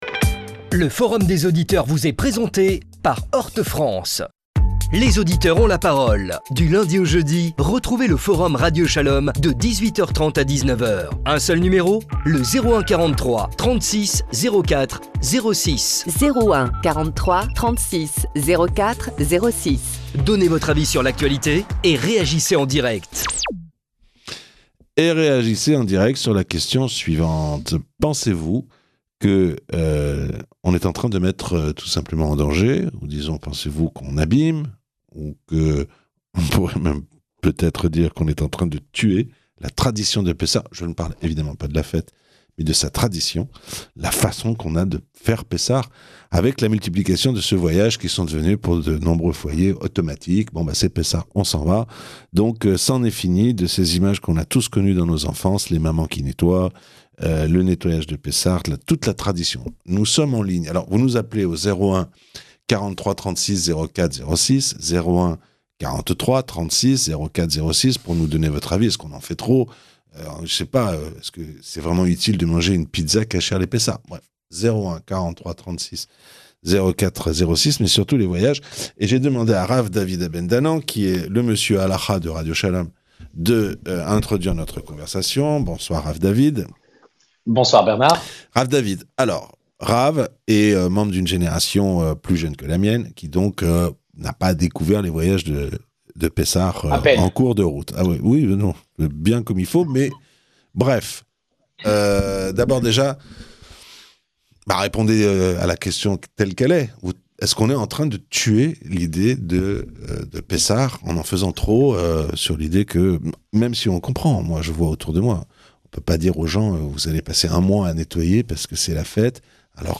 Chaude ambiance dans le Forum des auditeurs : les auditeurs se demandent si la systématisation des voyages à l'étranger à l'occasion de Pessah n'a pas tué la fête.